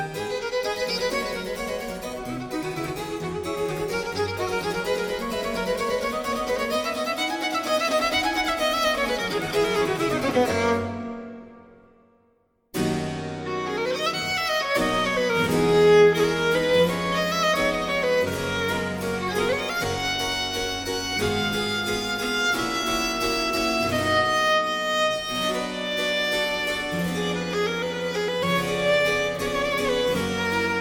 ut majeur